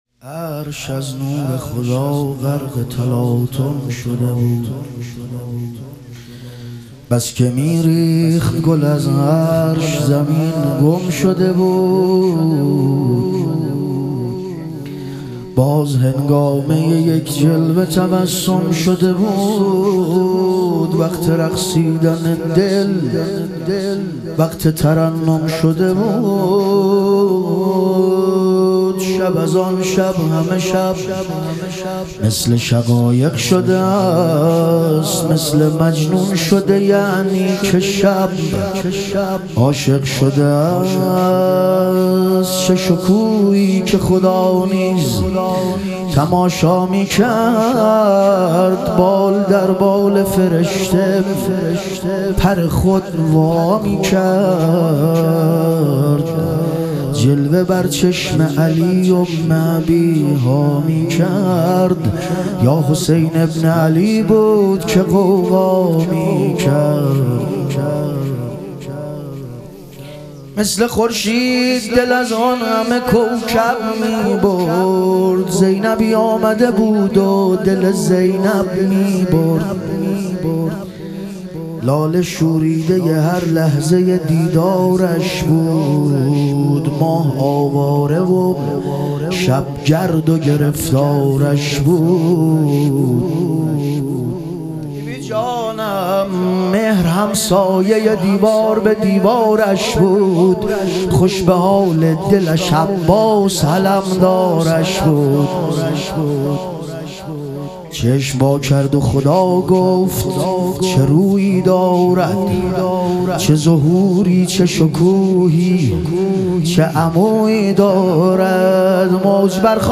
ظهور وجود مقدس حضرت رقیه علیها سلام - مدح و رجز